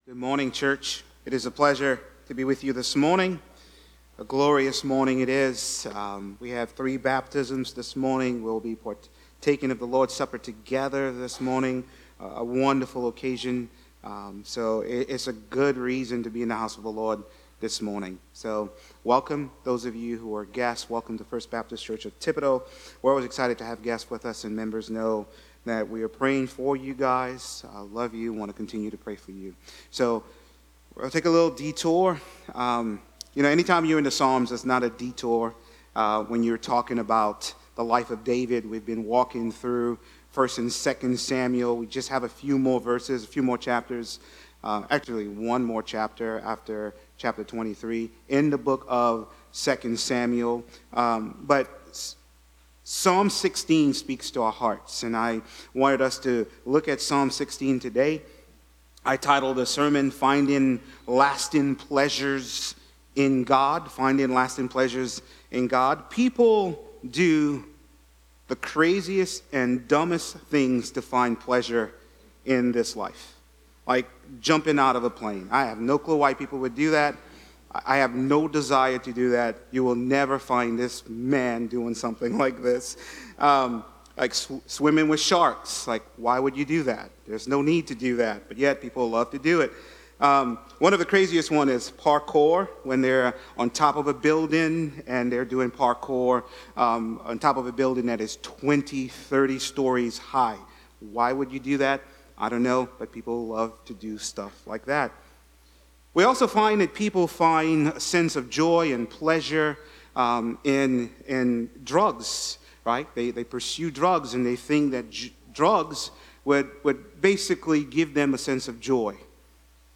These are sermons not associated with any particular sermon series.